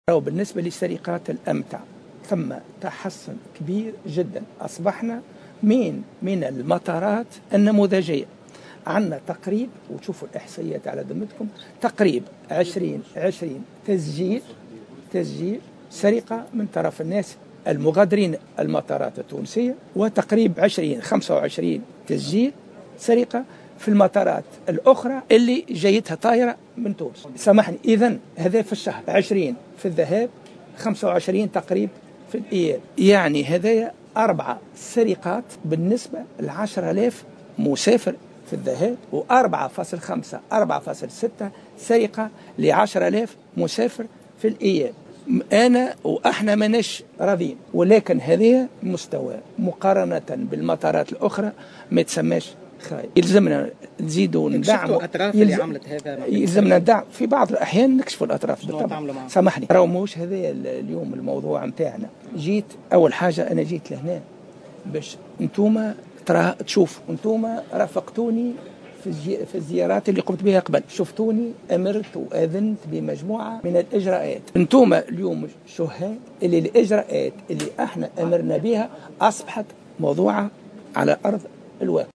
أعلن وزير النقل محمود بن رمضان في تصريح للجوهرة أف أم على هامش زيارة تفقدية لمطار تونس قرطاج اليوم الأربعاء 02 ديسمبر 2015 عن تراجع السرقات في المطارات التونسية التي أصبحت من المطارات النموذجية وفق قوله.